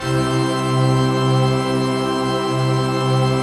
CHRDPAD047-LR.wav